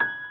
piano_last32.ogg